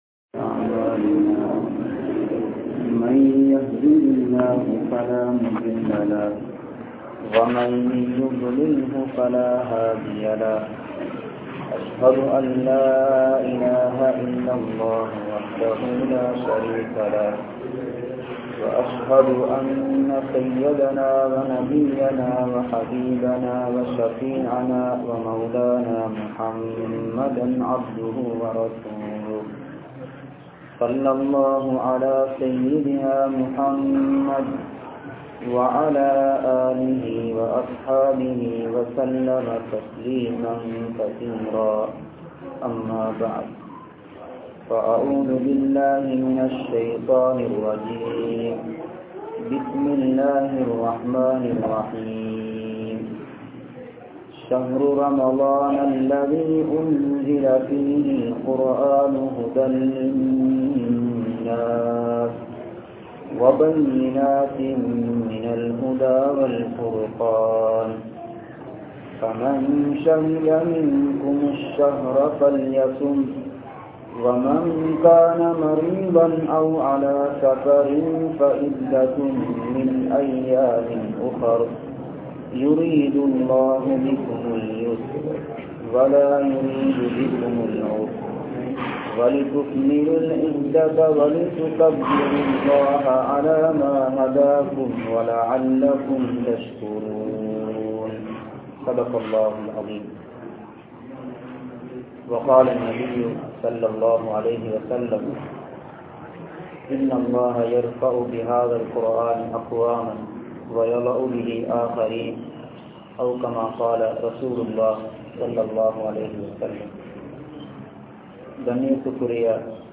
Al Quranai Oathuvoam (அல்குர்ஆனை ஓதுவோம்) | Audio Bayans | All Ceylon Muslim Youth Community | Addalaichenai